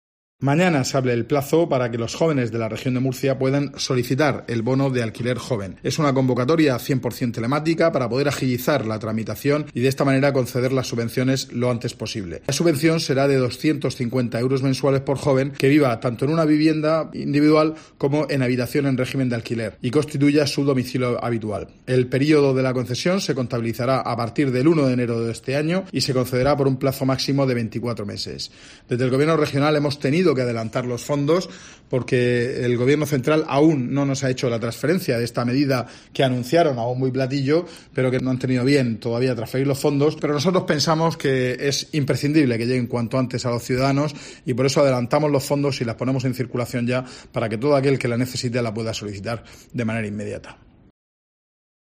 José Ramón Díez de Revenga, consejero de Fomento e Infraestructuras